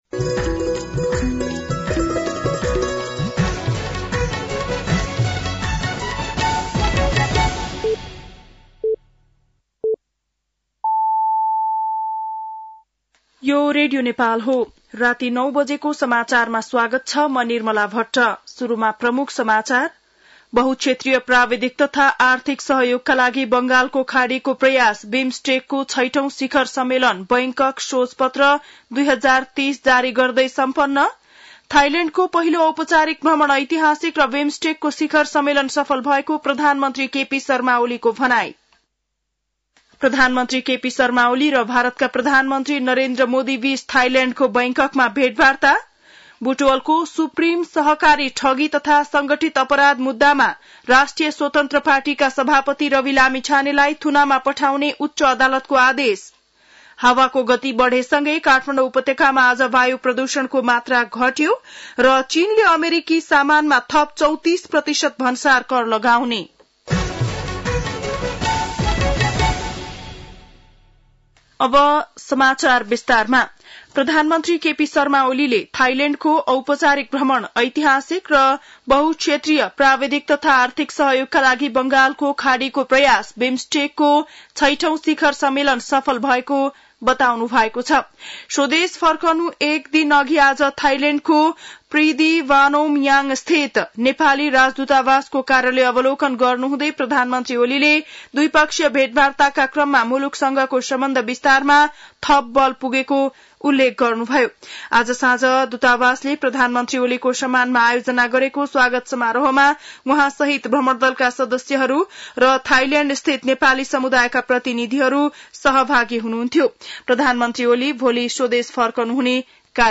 बेलुकी ९ बजेको नेपाली समाचार : २२ चैत , २०८१